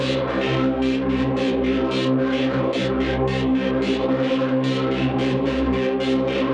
Index of /musicradar/dystopian-drone-samples/Tempo Loops/110bpm
DD_TempoDroneC_110-C.wav